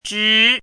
chinese-voice - 汉字语音库
zhi2.mp3